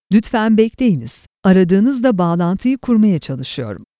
Native Turkish voice artist with a professional business voice.
Please Hold.mp3